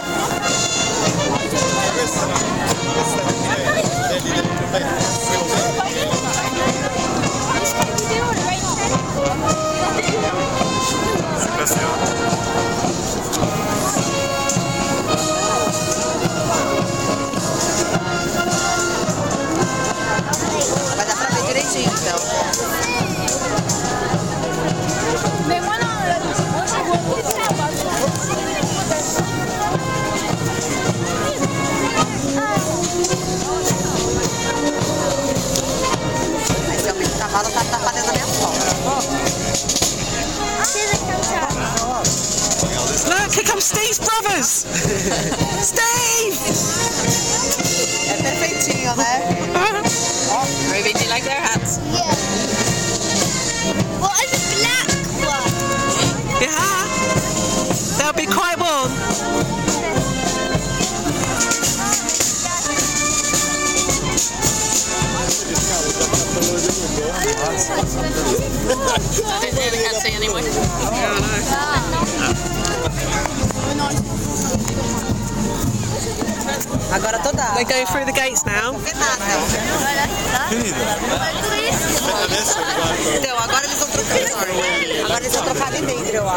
Changing the Guard at Buckingham Palace